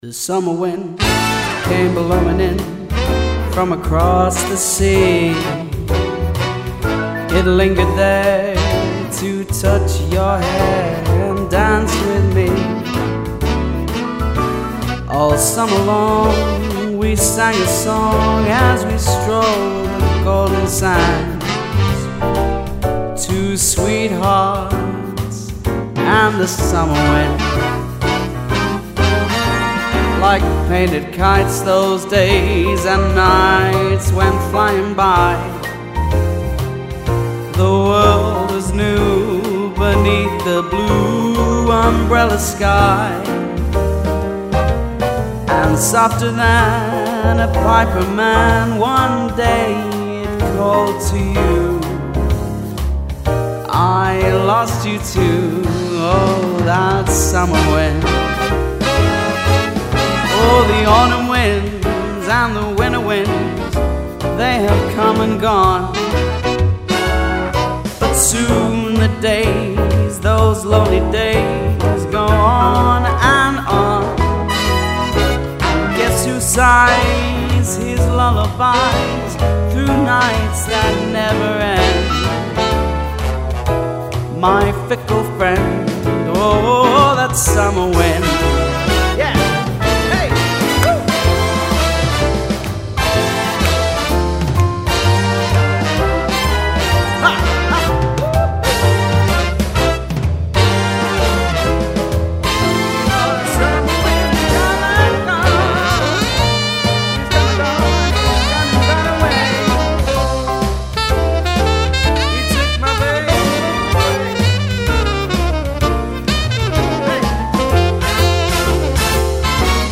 Swing Singers & Rat Pack Singers
swing and jazz
effortlessly capturing the Canadian crooner's sound